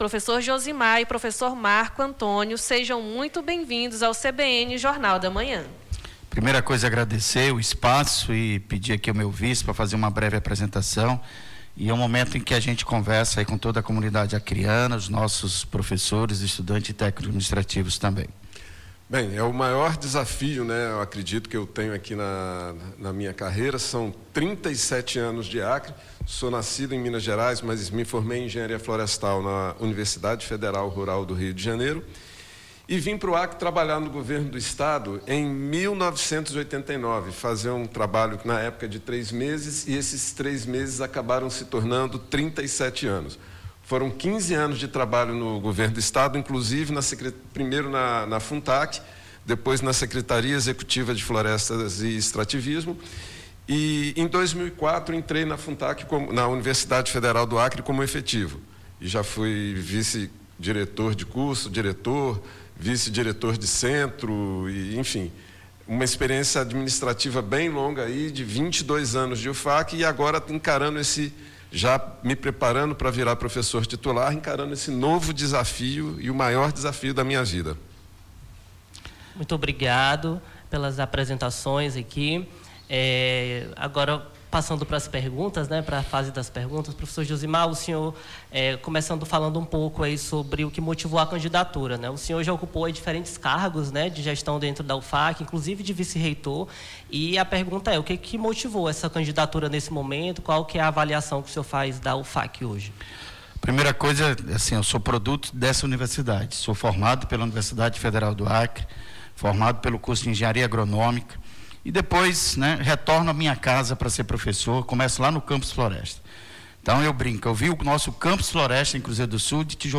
ENTREVISTA ELEIÇÃO UFAC